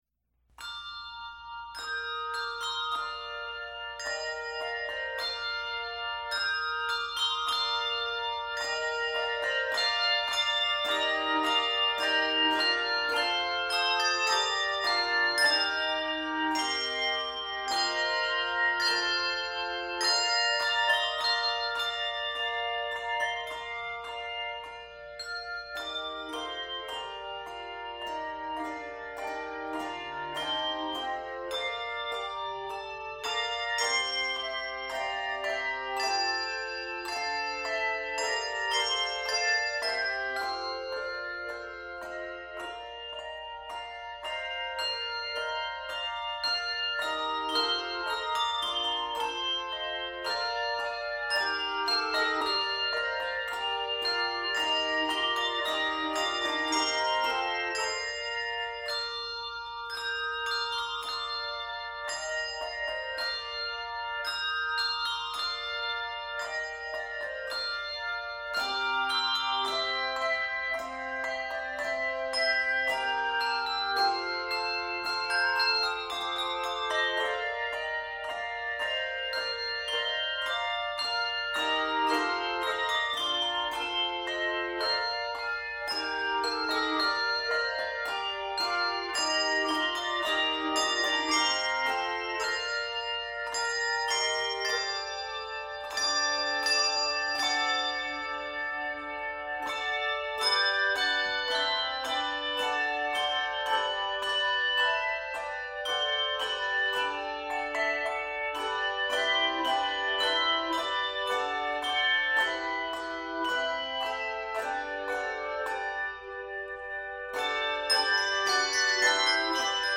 two- and three-octave handbell arrangements